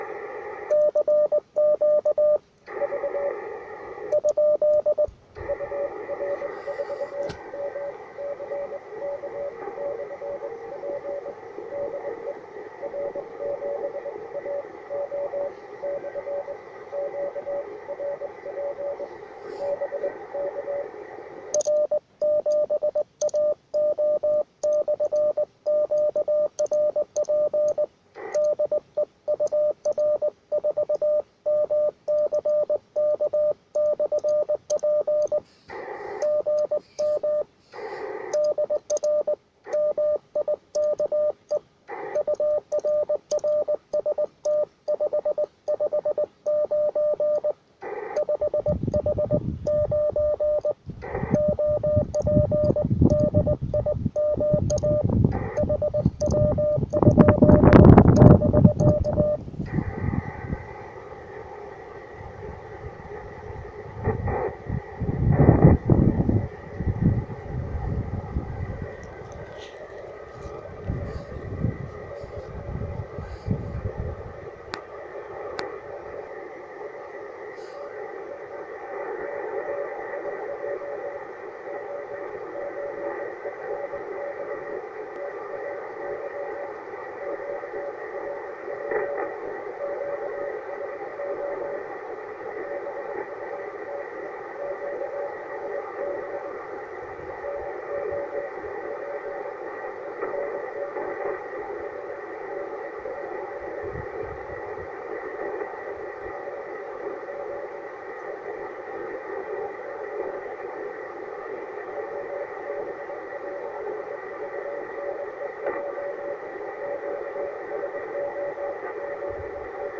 Причем это не локальный, а именно эфирный широкополосный шум с характерным призвуком.
Сигналы станций шли с большими, но ооочень медленными QSB.
Порой на 14060 стоял "пчелиный рой" и ничего разобрать нельзя было.